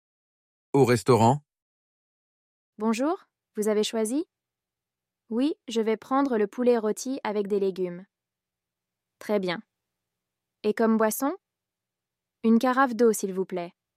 Dialogues en Français